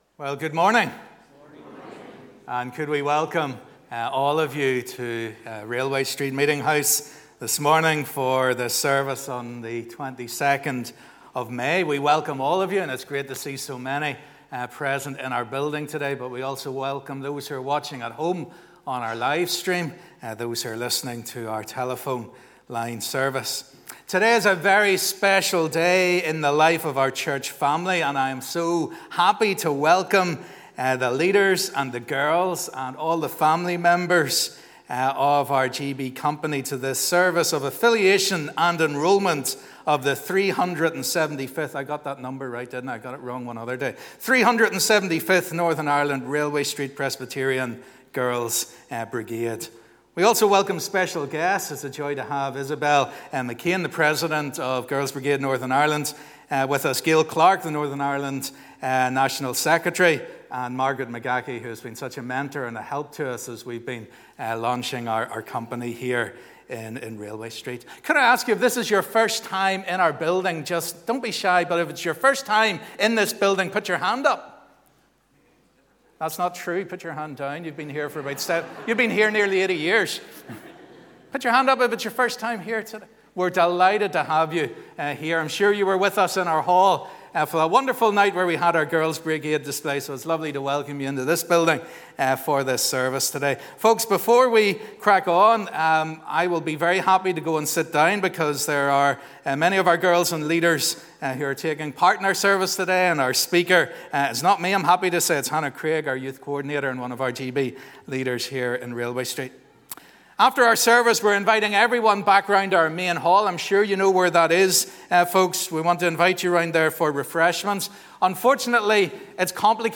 GB Affiliation Service